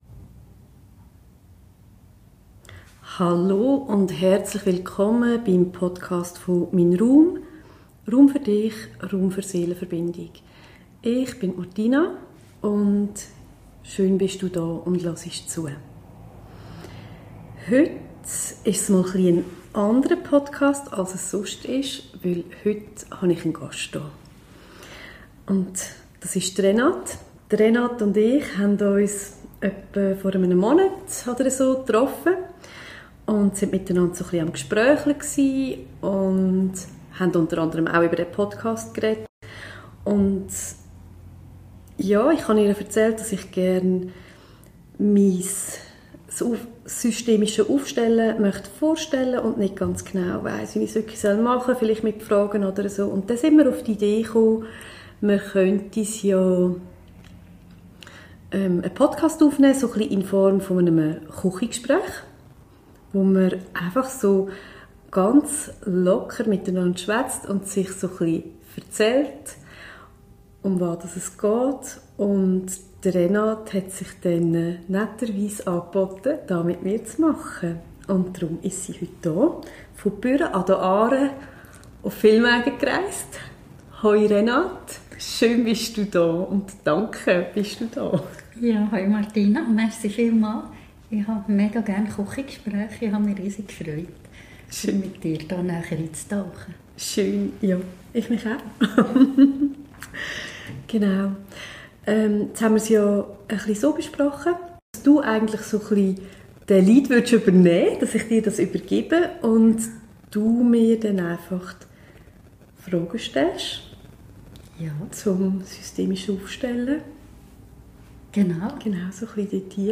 Wir führen ein Küchengespräch zum Thema systemisches Aufstellung